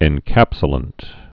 (ĕn-kăpsə-lənt)